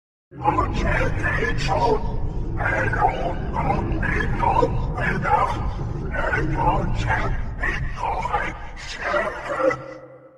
Play, download and share Nihilus original sound button!!!!
darth_nihilus_voice_test_2mp3converter.mp3